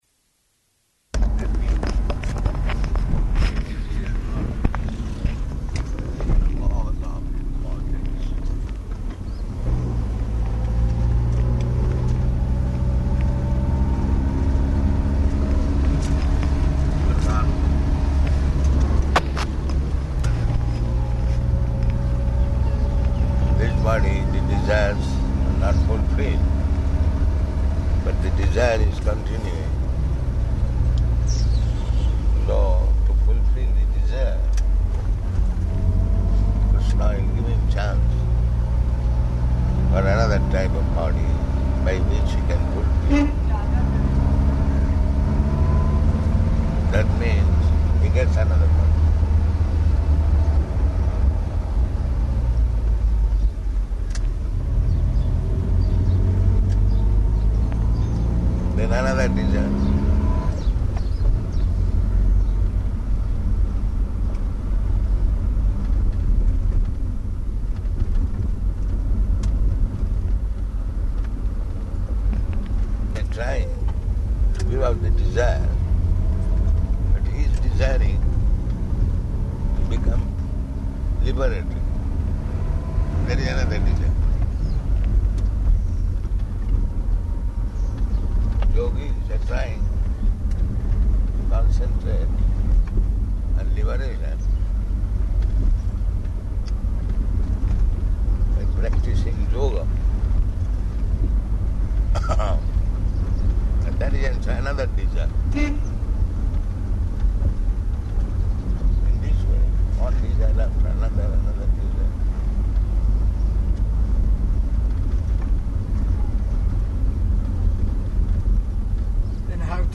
Type: Walk
Location: Delhi